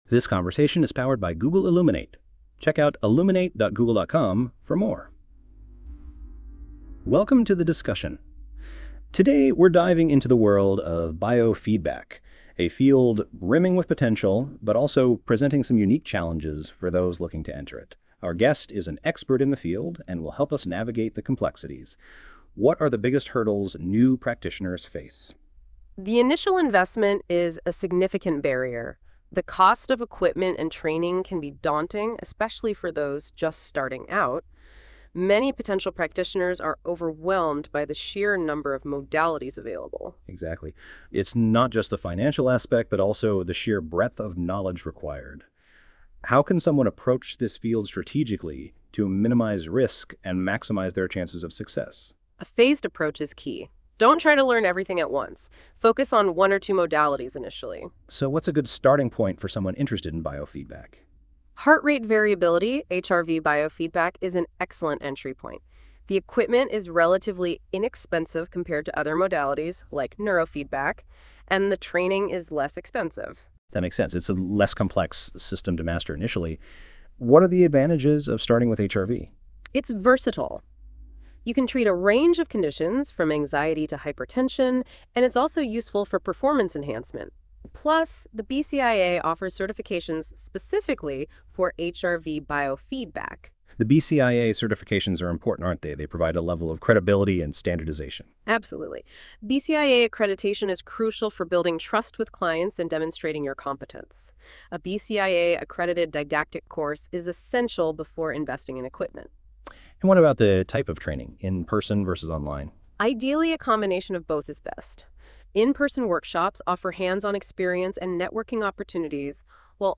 Please click on the podcast icon for a Google Illuminate discussion.